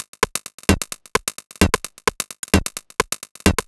Alphatown2 130bpm.wav